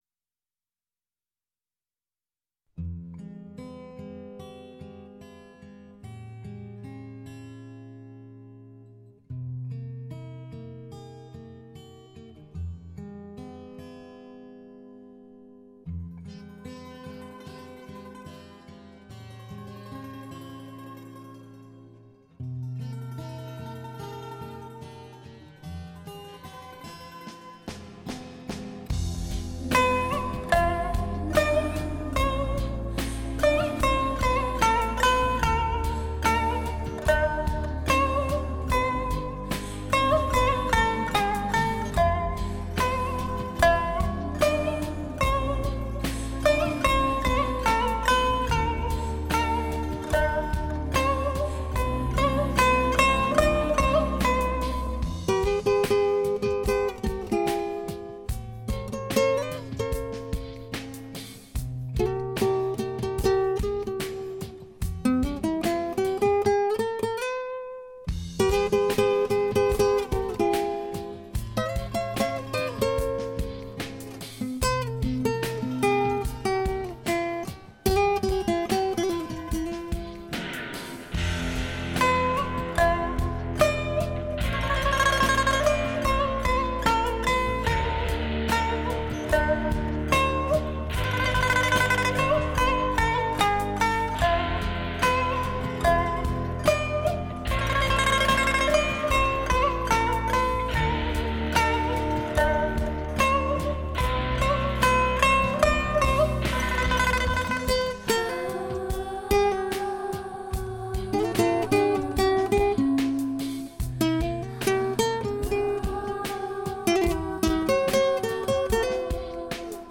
琵琶演绎黄金协奏，缔造听觉瑰丽奢华盛朝
美妙绝伦的音乐发烧天碟，独具韵味，大器澎湃
我们还能听见这次琵琶结合各种风韵独特的乐器，
段段动人音律时而甜美，时而神秘，时而壮阔，